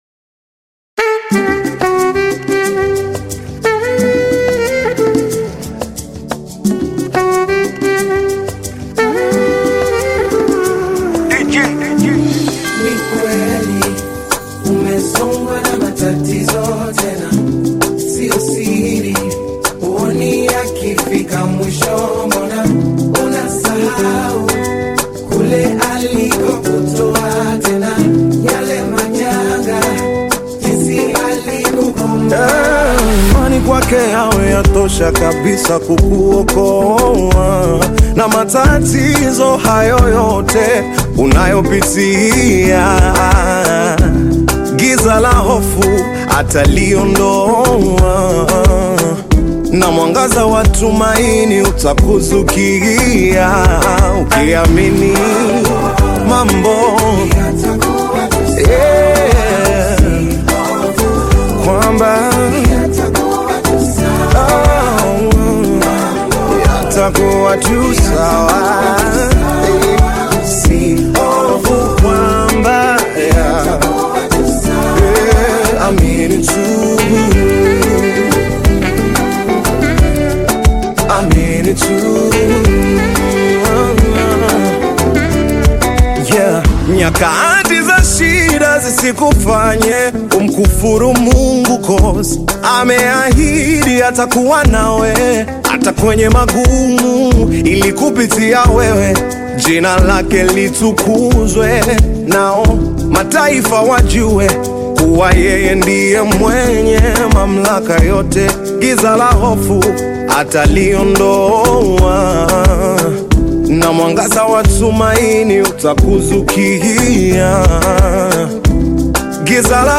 Tanzanian bongo flava artist singer and songwriter
gospel song
African Music